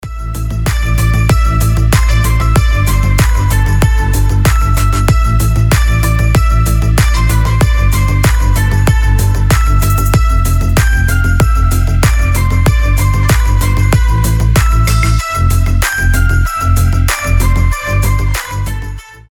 • Качество: 320, Stereo
поп
без слов
красивая мелодия
Флейта
Красивый проигрыш